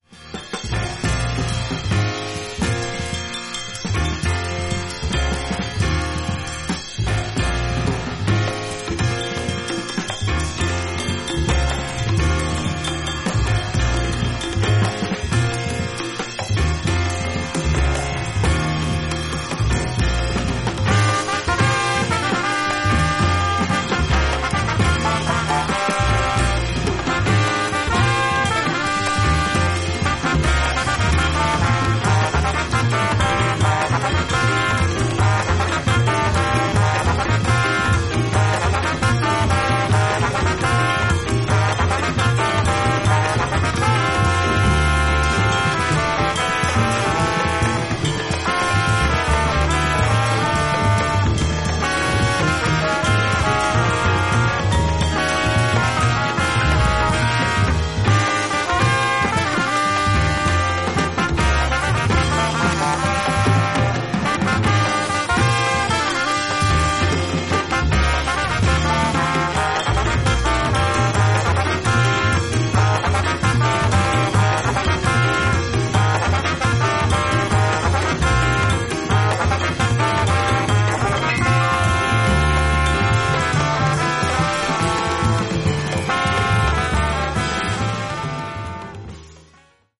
疾走感溢れるアフロ・キューバン・ジャズ